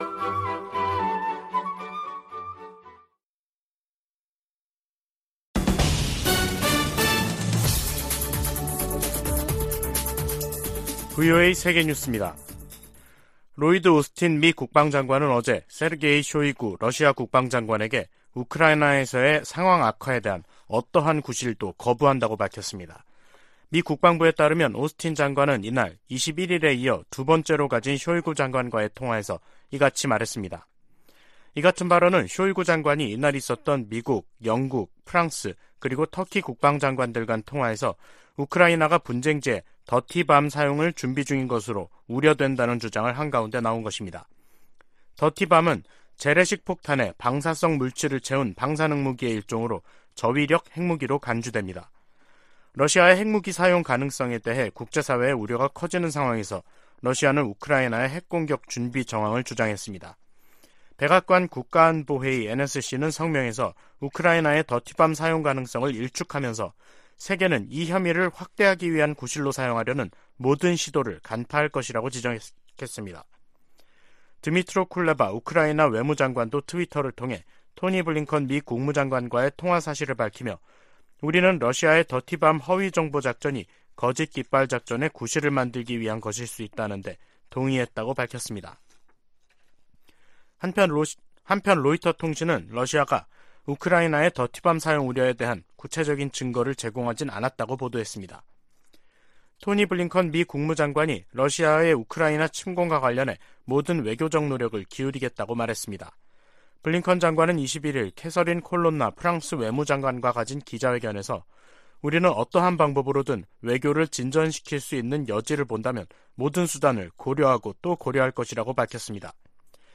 VOA 한국어 간판 뉴스 프로그램 '뉴스 투데이', 2022년 10월 24일 2부 방송입니다. 한국 합동참모본부는 24일 서해 백령도 서북방에서 북한 상선이 북방한계선(NLL)을 침범해 경고 통신과 경고사격으로 퇴거 조치했다고 밝혔습니다. 북대서양조약기구는 한국과 함께 사이버 방어와 비확산 등 공통 안보 도전에 대응하기 위해 관계를 강화하는데 전념하고 있다고 밝혔습니다. 국제자금세탁방지기구가 북한을 11년째 대응조치를 요하는 '고위험 국가'에 포함했습니다.